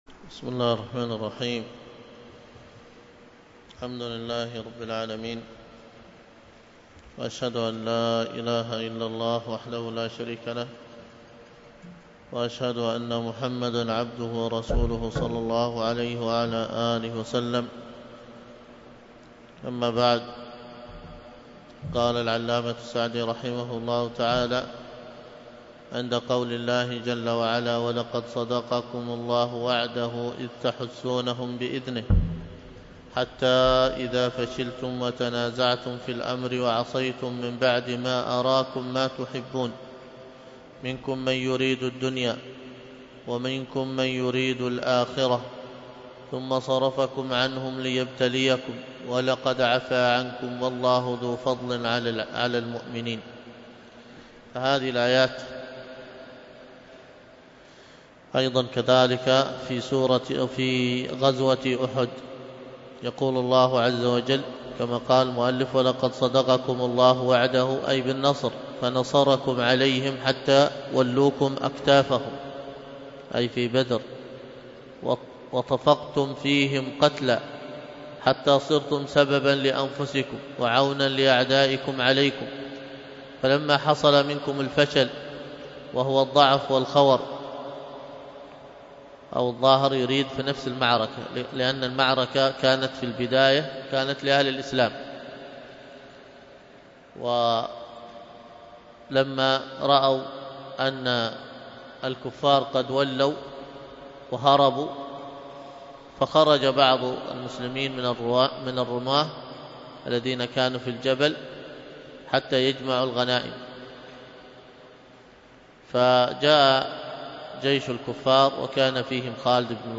الدروس القرآن الكريم وعلومه